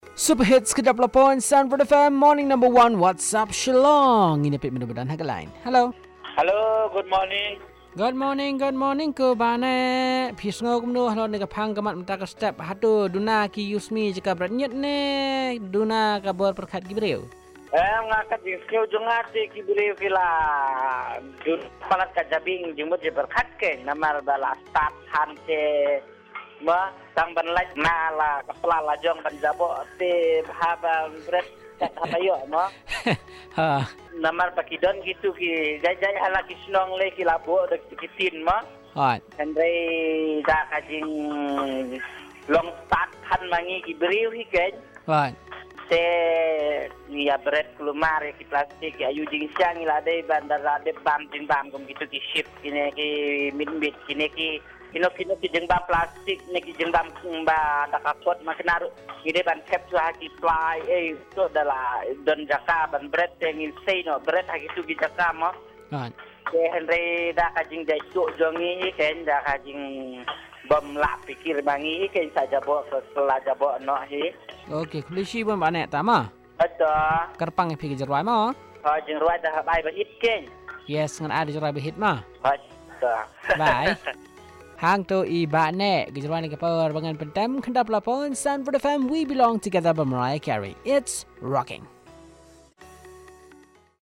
Caller 2 on Littering